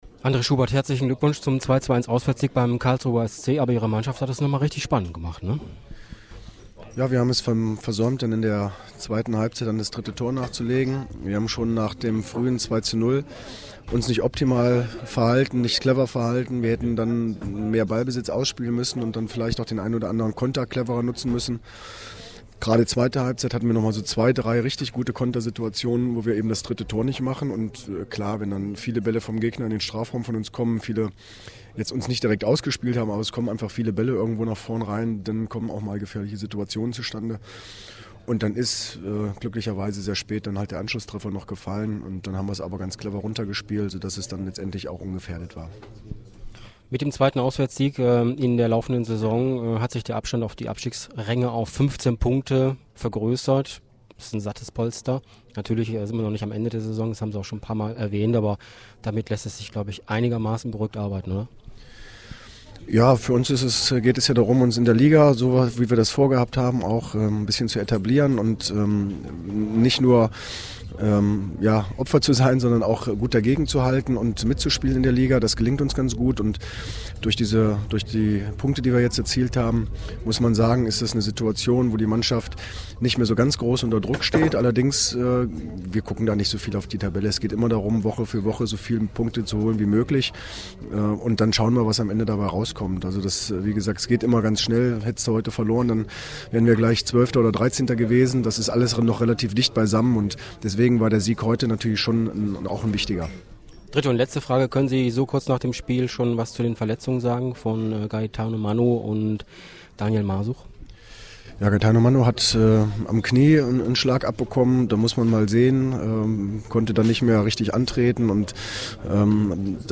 AUDIOKOMMENTAR